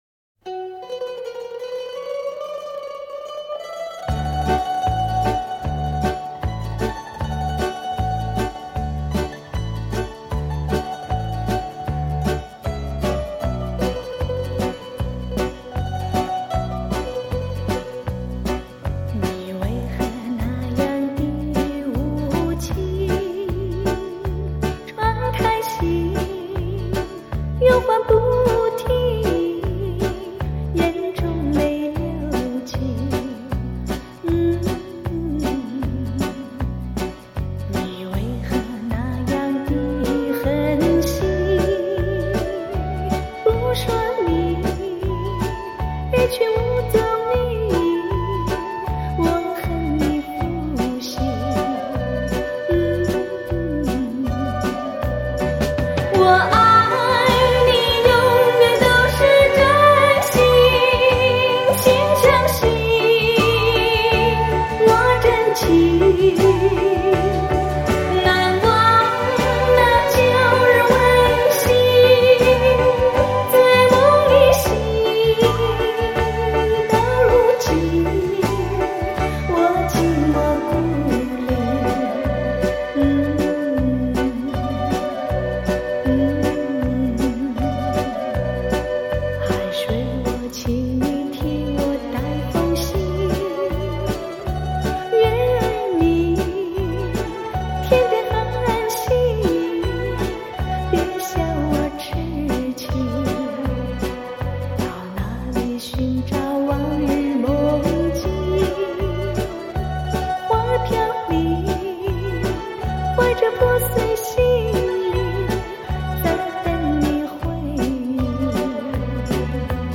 将100KHz宽频带/24bit音频信息载入
音色更接近模拟(Analogue)声效
强劲动态音效中横溢出细致韵味